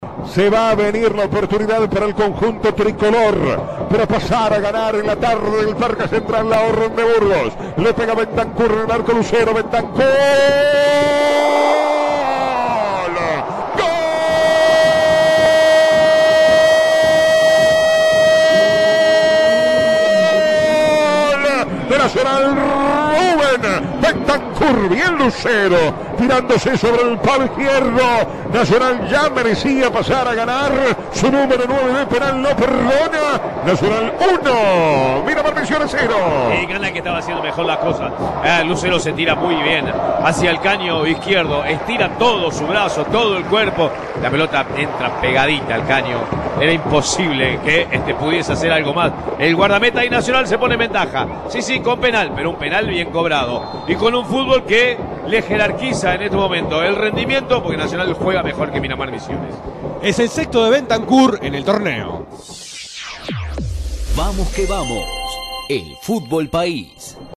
La goleada tricolor en la voz del equipo de Vamos que Vamos